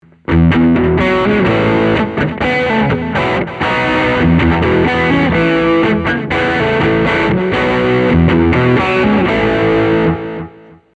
VINTAGE 1950s Gibson ES-225 Thinline Guitar
Here are 5 quick, 1-take MP3 sound files of myself playing this guitar, to give you an idea of what to expect. The guitar has great tone, sustain, and body, and is also capable of some nice controlled feedback effects. These tracks are all recorded using the HB position, playing through a Peavey Studio Pro 112 amp with a Behringer Virtualizer effects unit, using a Shure SM57 mic recorded straight into a Sony PCM D1 flash recorder, and MP3s were made in Logic, with no EQ or effects.
(Original, in Am)